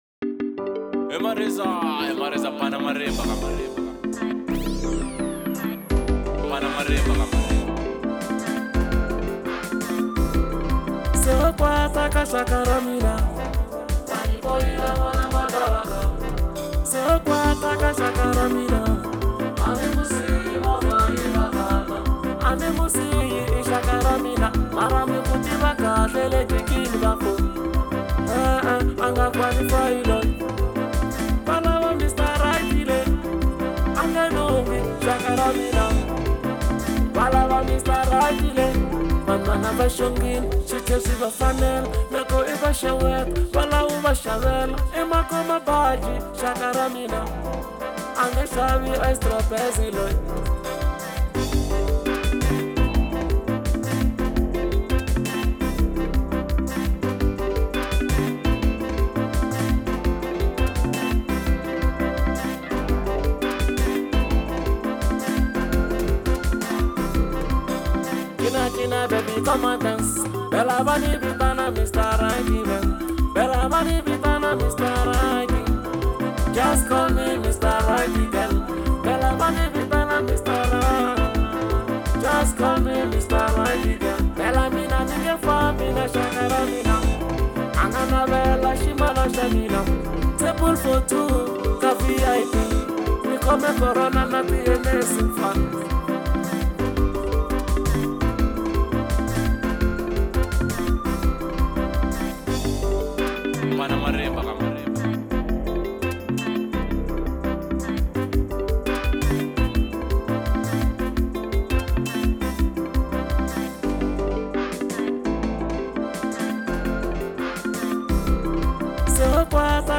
04:04 Genre : Xitsonga Size